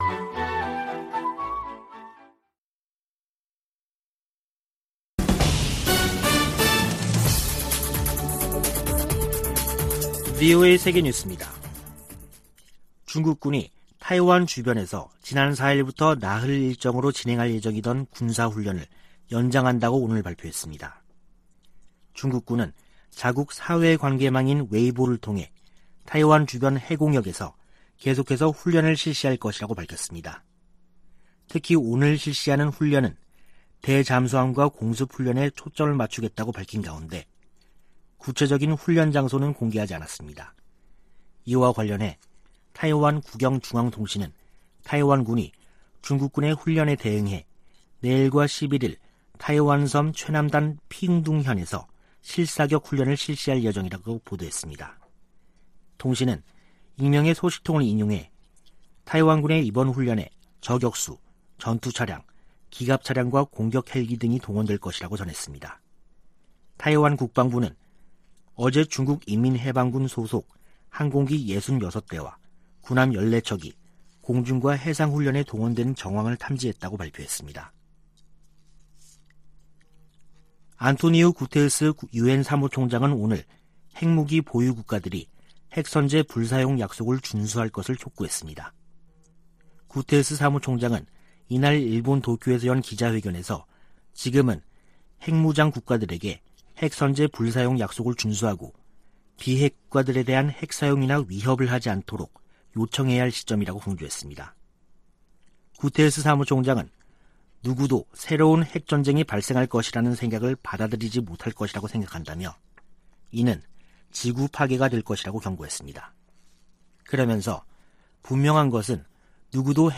VOA 한국어 간판 뉴스 프로그램 '뉴스 투데이', 2022년 8월 8일 2부 방송입니다. 미국의 핵 정책 당국자들이 북한을 주요 핵 위협 가운데 하나로 꼽았습니다. 북한이 7차 핵실험을 위한 핵 기폭장치 실험을 했다는 유엔 전문가패널 보고서가 알려진 가운데 미국의 핵 전문가들은 이것이 ‘임계 전 핵실험’을 의미한다고 말했습니다. 낸시 펠로시 미 하원의장은 아시아 순방을 마치면서 한국 방문에서 논의의 상당 부분이 북한의 위협에 대한 것이었다고 설명했습니다.